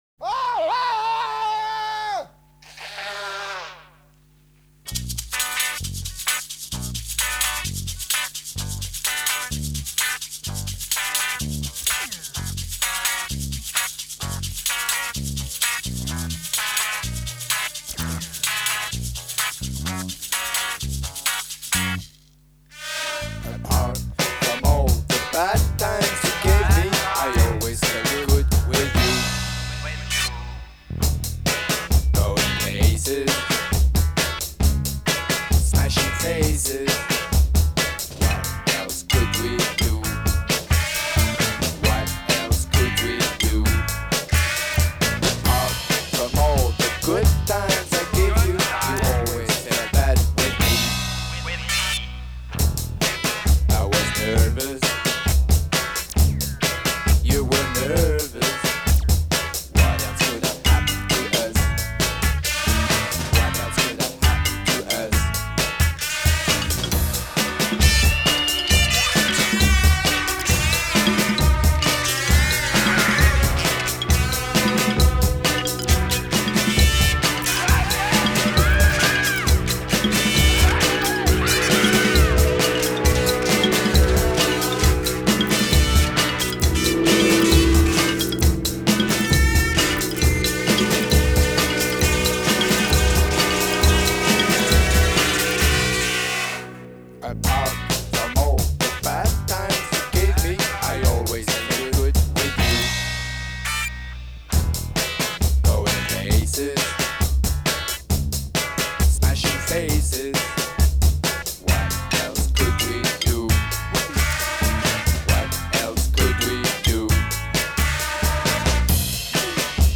הקראוטרוק